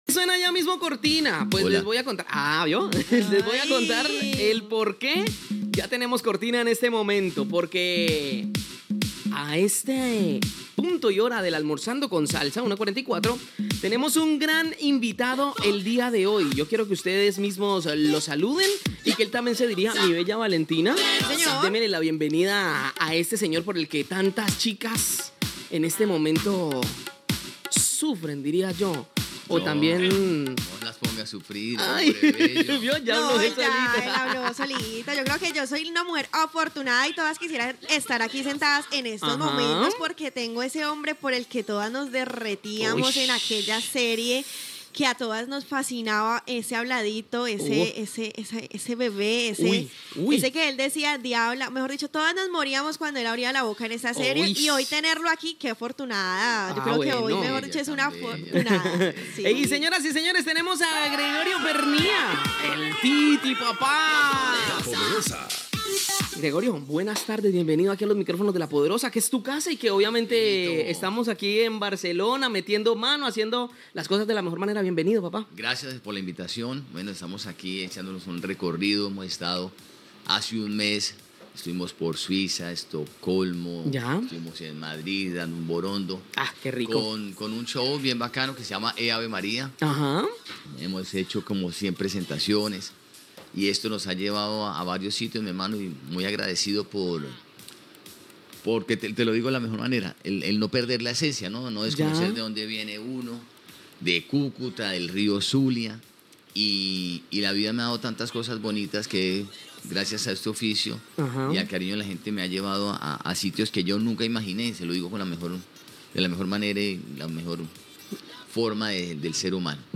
Presentació i entrevista a l'actor colombià Gregorio Pernía
Entreteniment